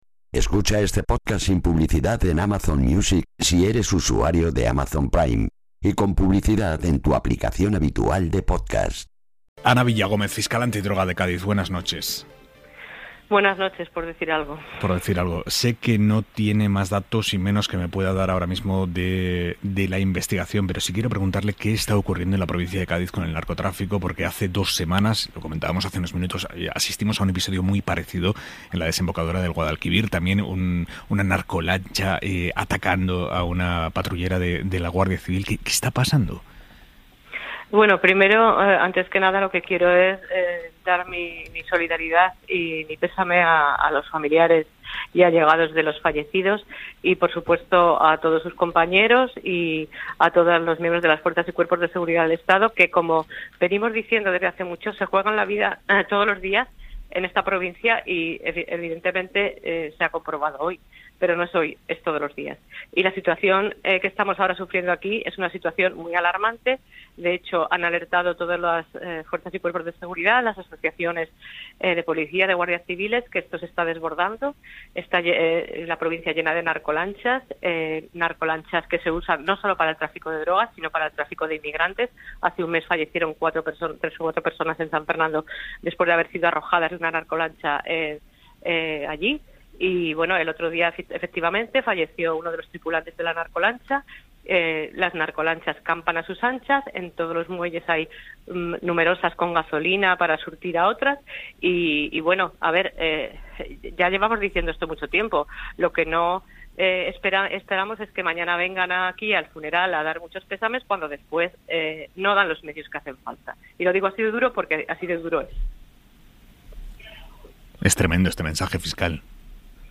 Villagómez, en una entrevista afirma que la situación es muy preocupante por la presencia de narcolanchas dentro de los puertos y «nadie pone medios para evitarlo».